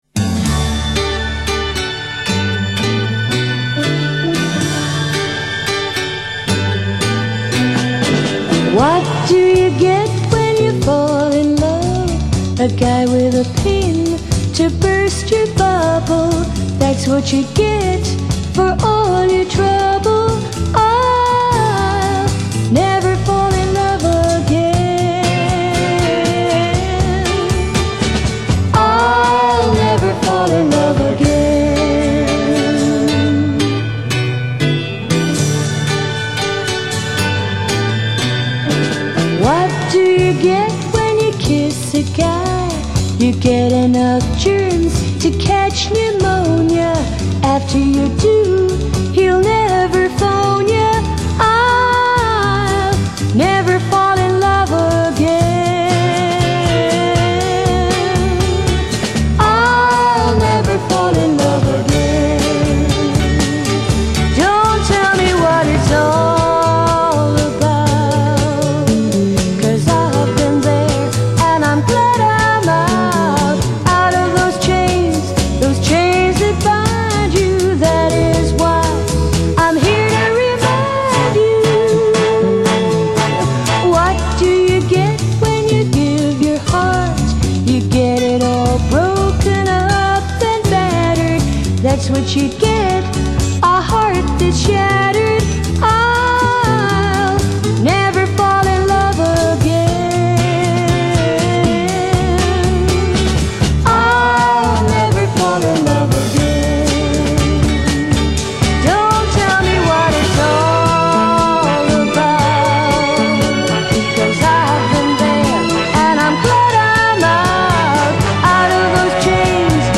语    种：纯音乐
[广告语] 乡村宁静雅致，民谣清新质朴。